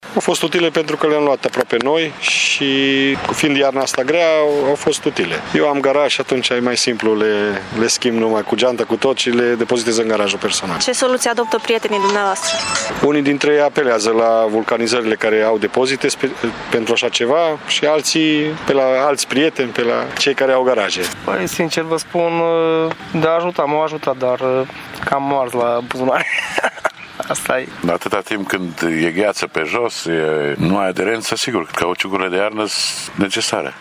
Șoferii târgumureșeni nu se grăbesc să își schimbe anvelopele de vară, dar sunt conștienți de importanța echipării mașinilor cu anvelope corespunzătoare sezonului: